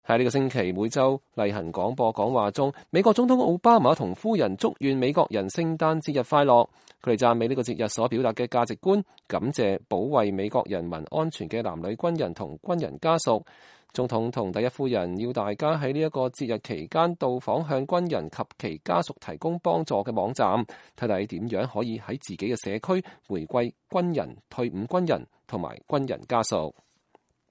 在本星期的每週廣播講話中，美國總統奧巴馬和夫人祝願美國人聖誕和節日快樂。他們讚美這個節日所表達的價值觀，感謝保衛美國人民安全的男女軍人和軍人家屬。